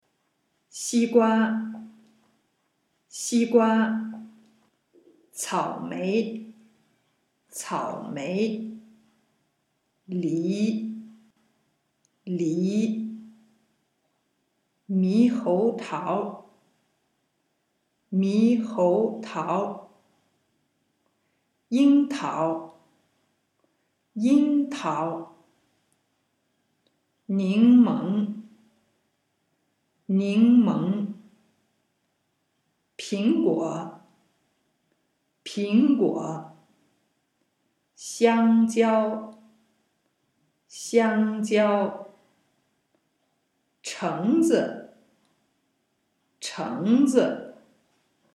MP3-Sprachtraining